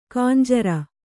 ♪ kanjara